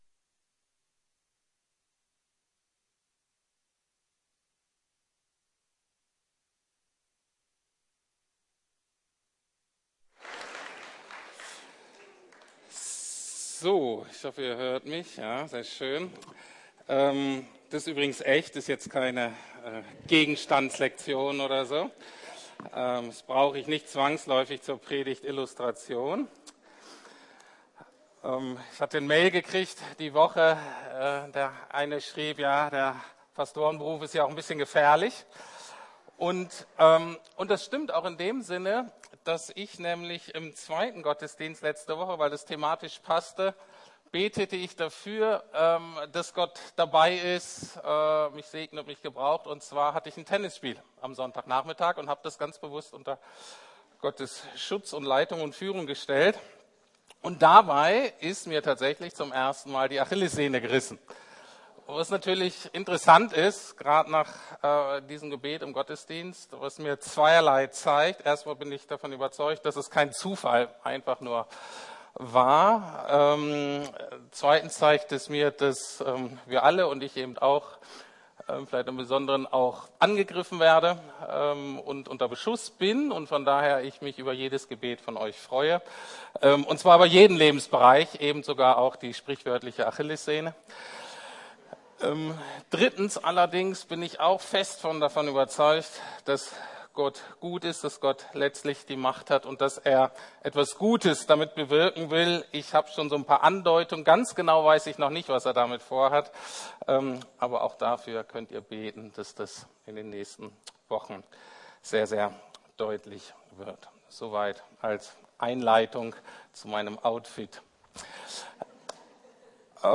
Das Erbe der Zukunft - Teil VI, Jesus Azubis ~ Predigten der LUKAS GEMEINDE Podcast